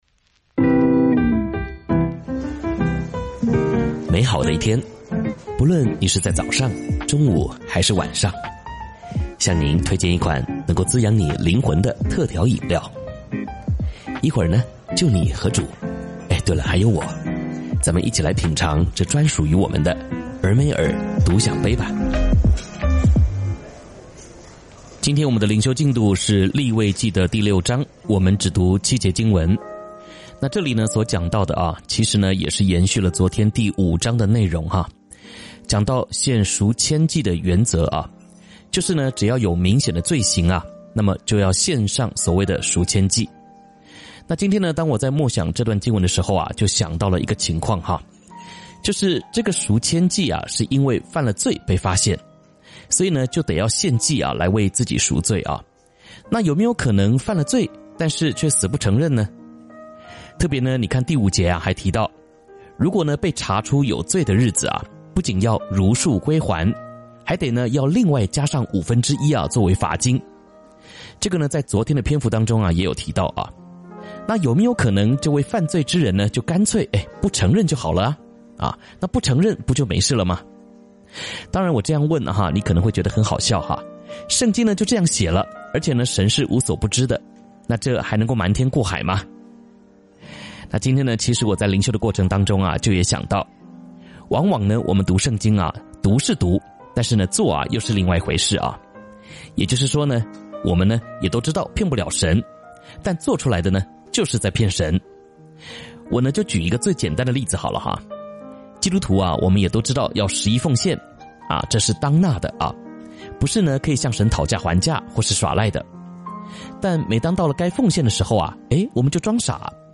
「天父爸爸說話網」是由北美前進教會Forward Church 所製作的多單元基督教靈修音頻節目。